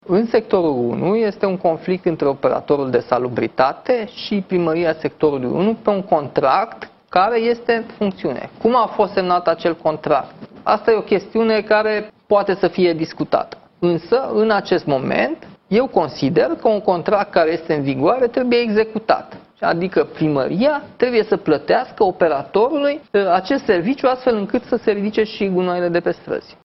Într-o intervenţie la Digi 24, primarul general, Nicuşor Dan, recomandă primăriei să plătească facturile şi să rezolve problema gunoaielor: